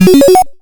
Soundspack 05 (8bit SFX 01-...
PowUp_03.mp3